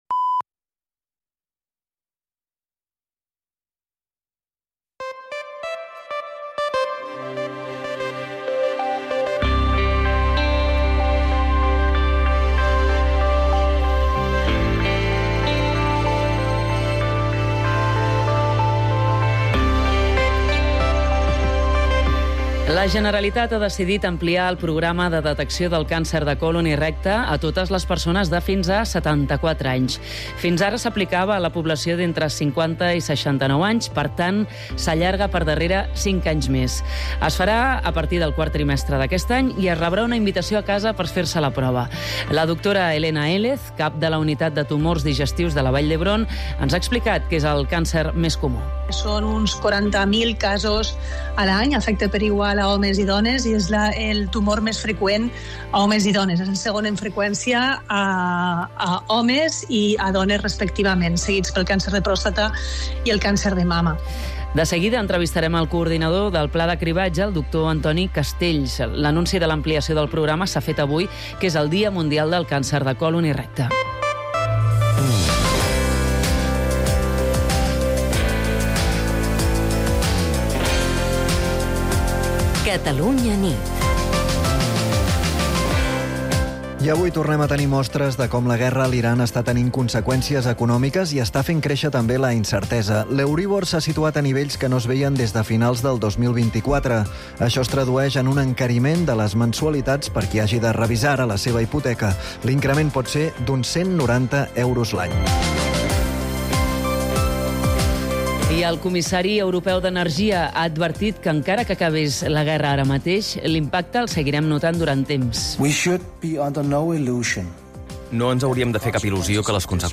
l'informatiu nocturn de Catalunya Ràdio